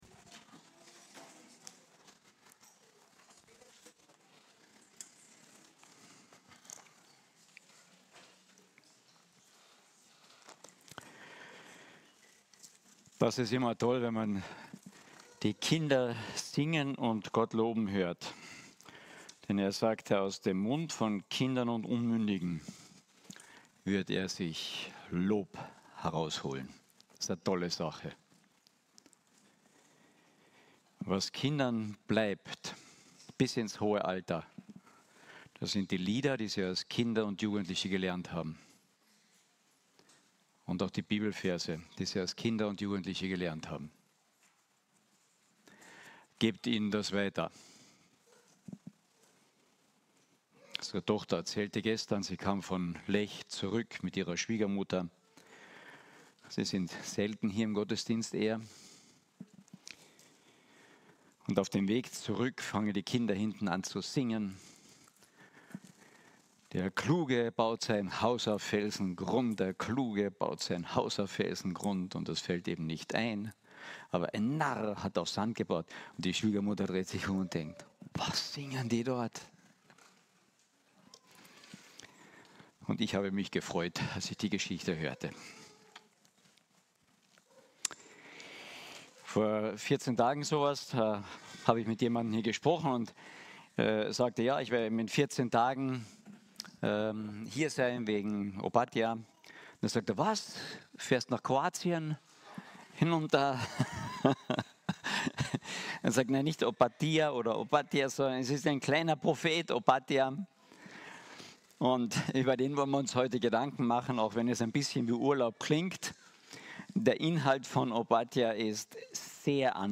Predigten – Archiv – FEG Klagenfurt